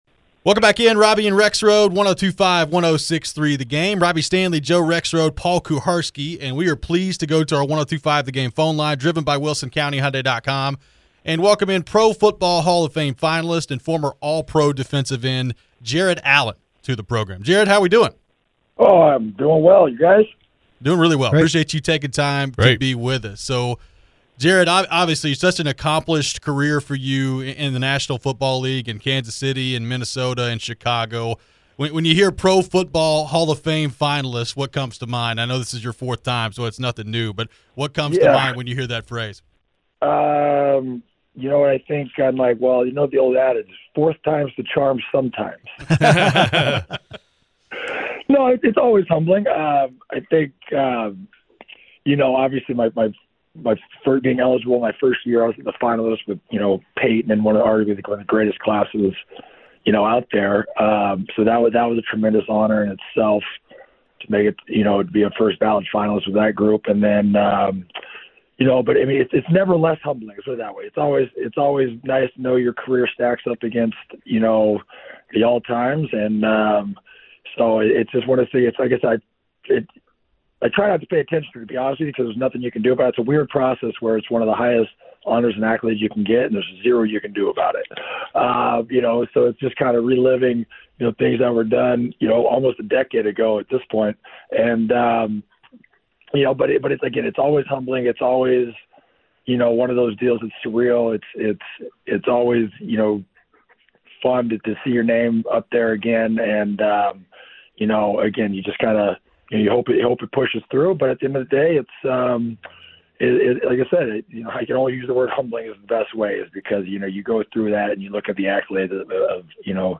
Jared Allen Interview (1-12-24)